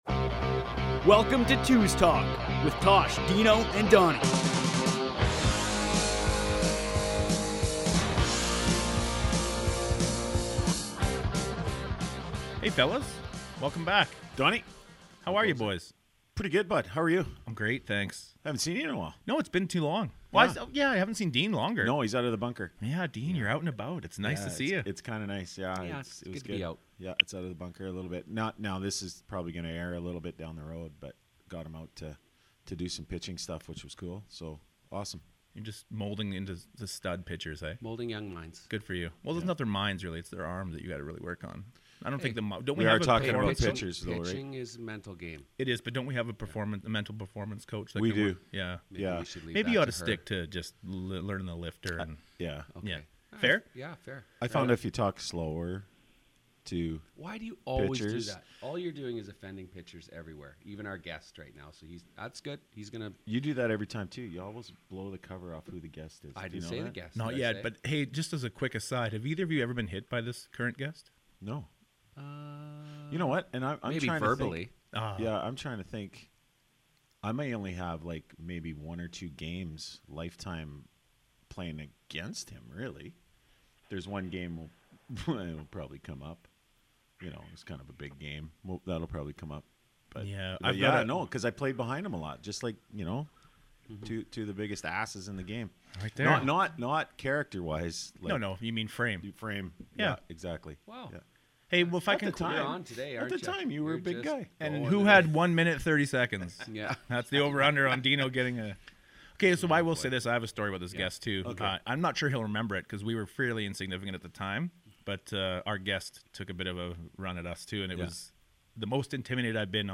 Lots of laughs and stories dominate this cast our former teammate.